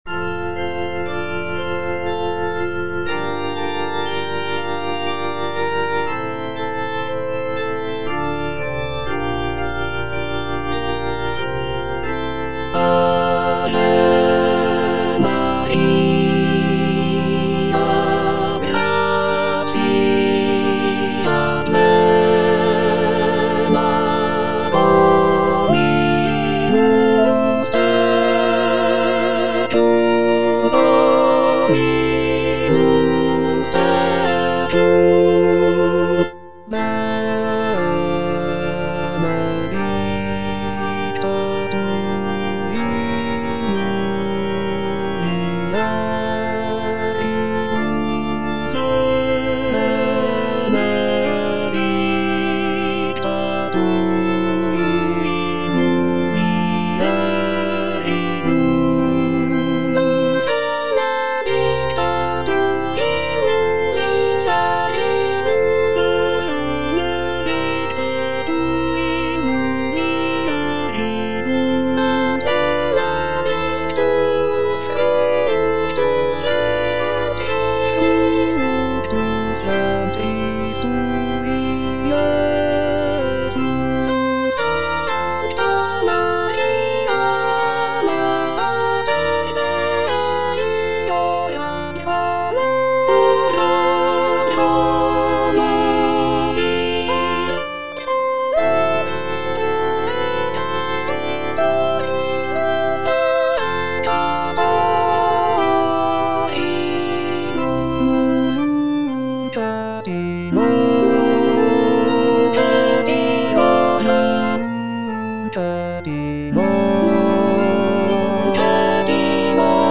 (All voices)